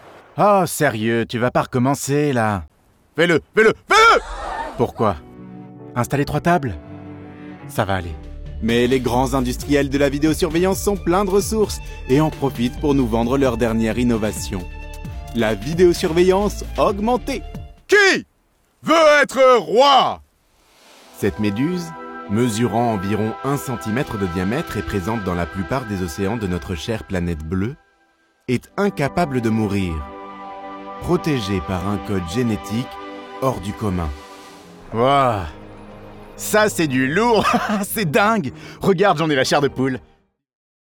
Comédien (théâtre) et voix-off.